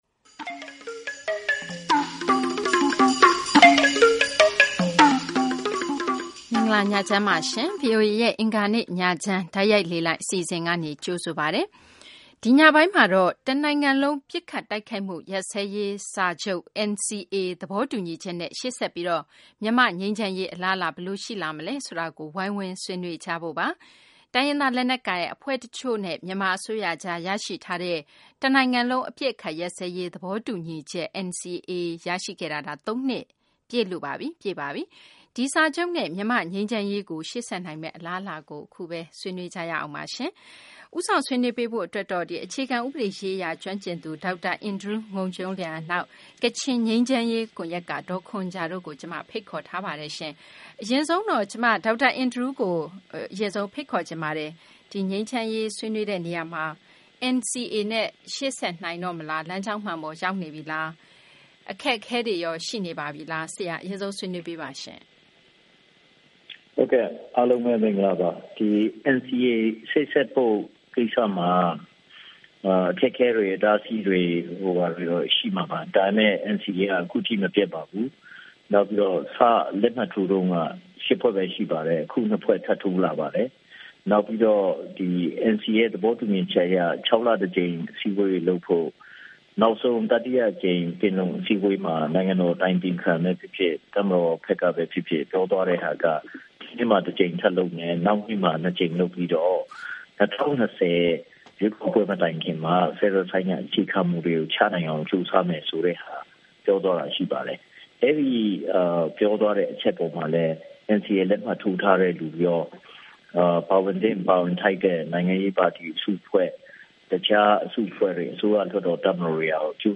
အင်္ဂါနေ့ညချမ်း တိုက်ရိုက်လေလှိုင်း အစီအစဉ် (အောက်တိုဘာလ ၉ ရက် ၂၀၁၈)